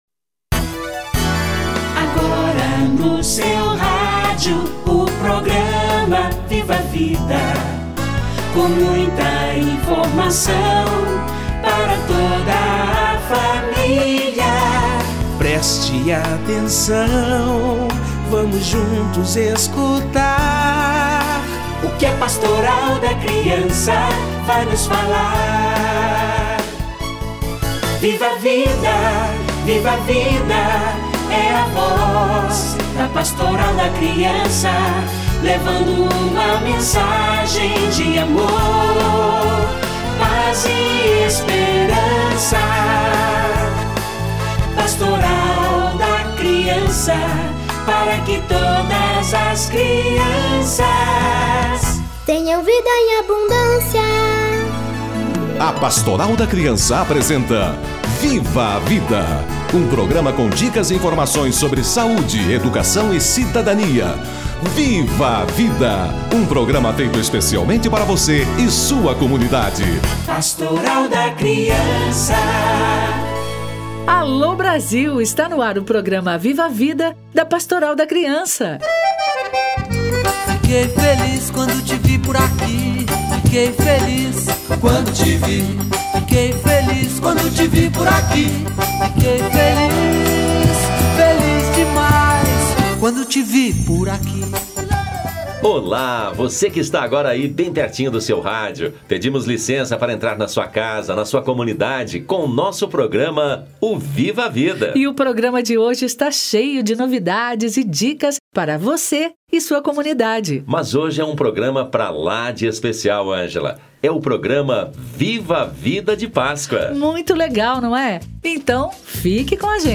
Tempo de relembrar e festejar a ressurreição de Jesus Cristo, que deu a vida por nós. Para relembrar o verdadeiro significado da Páscoa, convidamos Dom Anuar Battisti, Arcebispo emérito de Maringá.